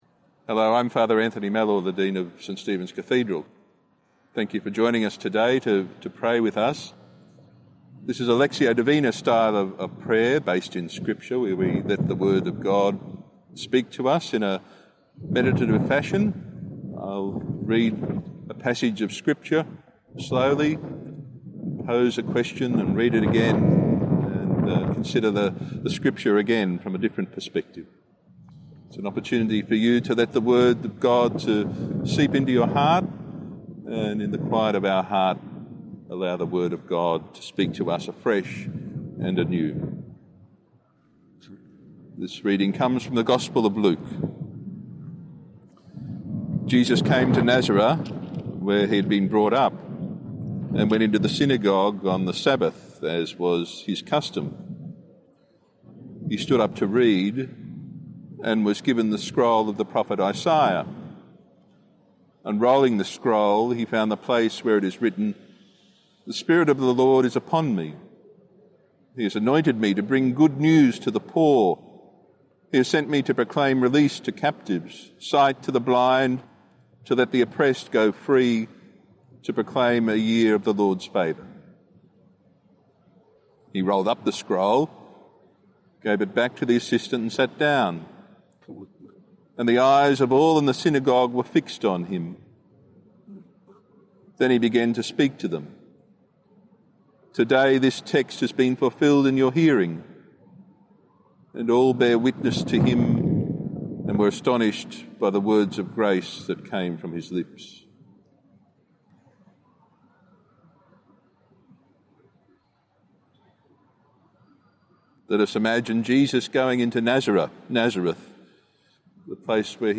Prayer and scriptural reflections, homilies and pastoral messages from Archbishop Mark Coleridge, catechesis and more.